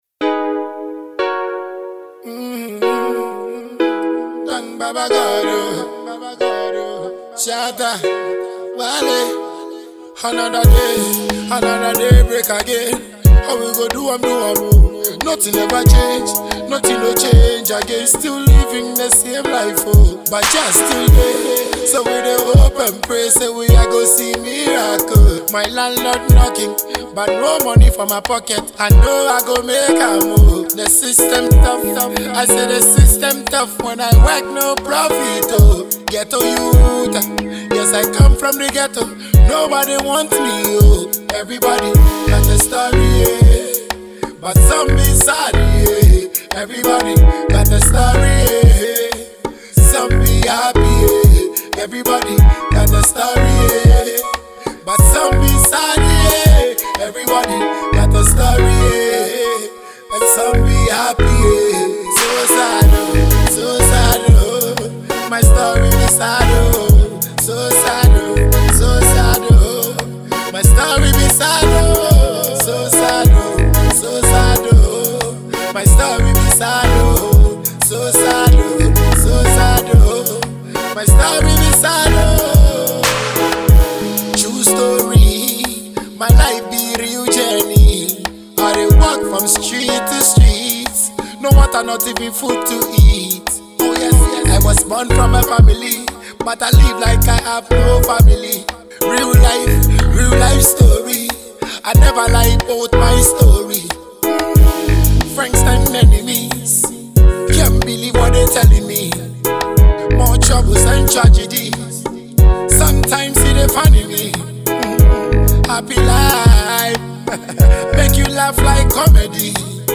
Ghana Music
Dancehall